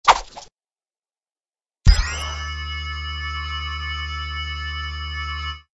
TL_large_magnet.ogg